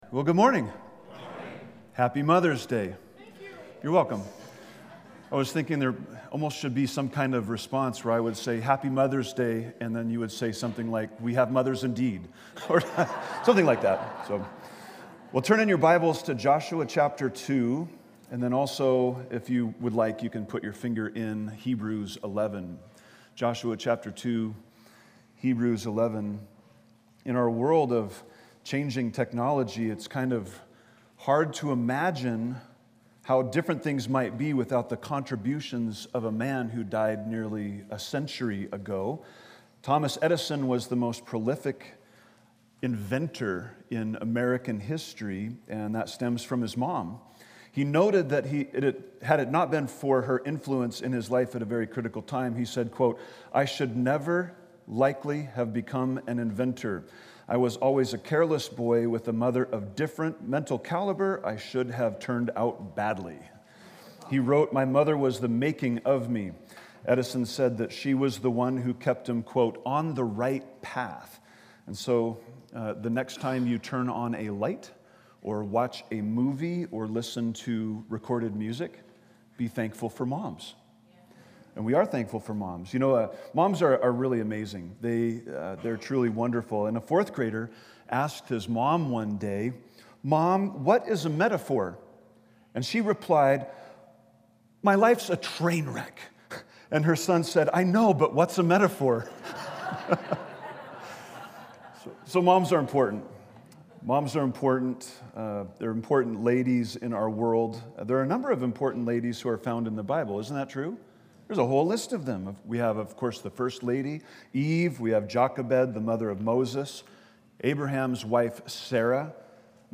Listen to Sermon Scripture: Judges 2, Hebrews 11:31, James 2:25